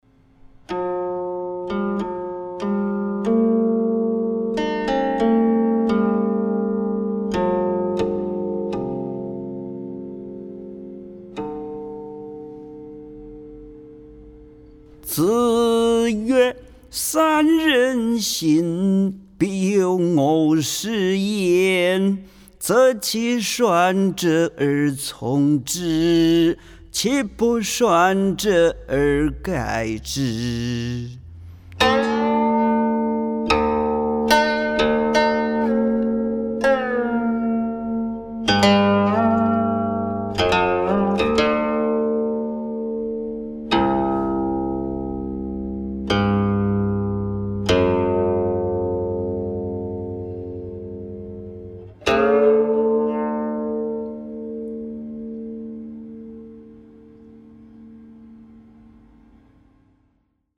吟誦